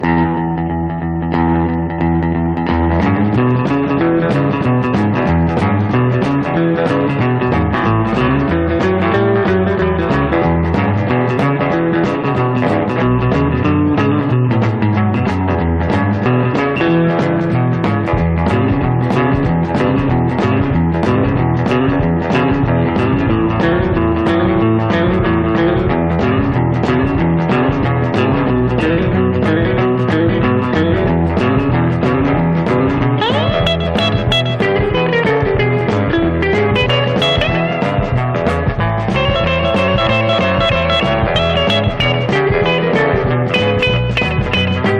Rock, Pop　France　12inchレコード　33rpm　Stereo